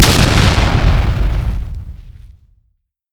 b_baozha1.mp3